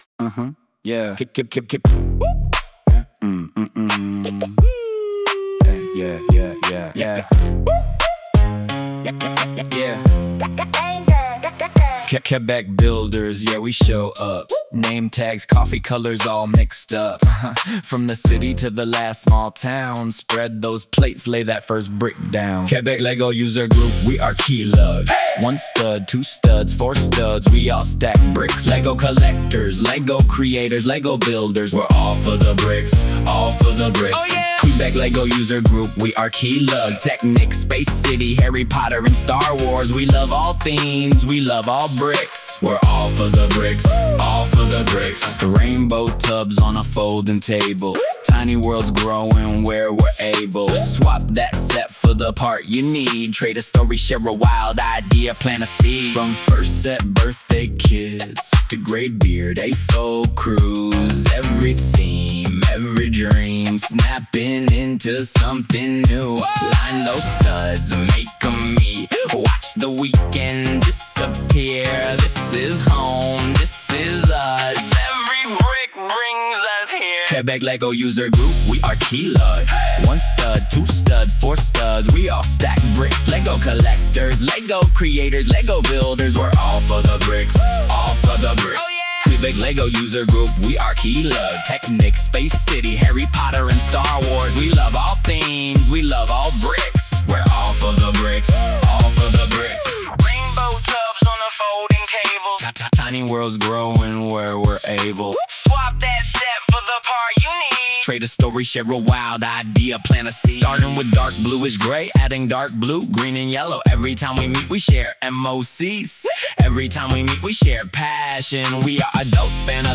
Enjoy P.S. : Oui c’est ma voix, mais avec des modifications parce que je chante comme une merde 🙂